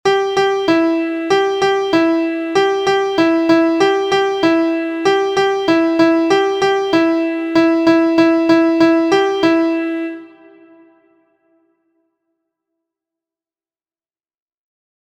• Origin: USA – Singing Game
• Key: C Major
• Time: 4/4
• Form: ABBC
• Pitches: beginners: Mi So
• Intervals: beginners: So\Mi, Mi/So
• Musical Elements: notes: quarter, eighth; singing alone, beginning vocal interval So\Mi